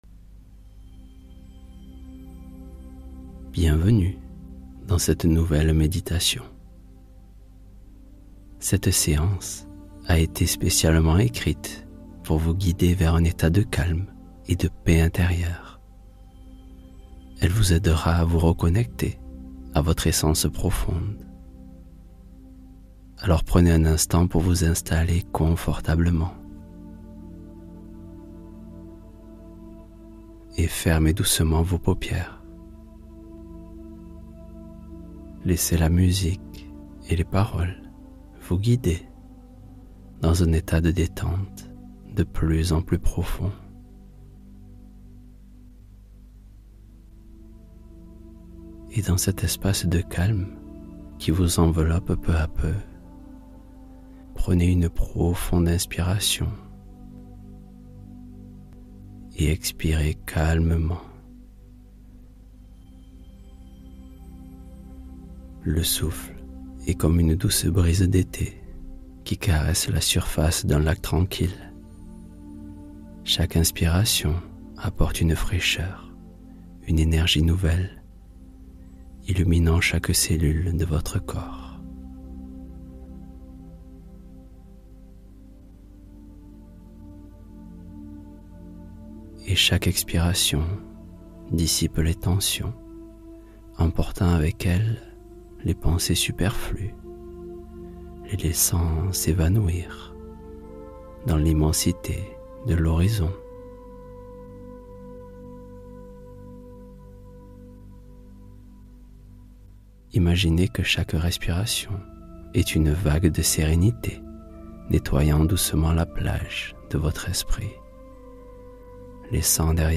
Relaxation Guidée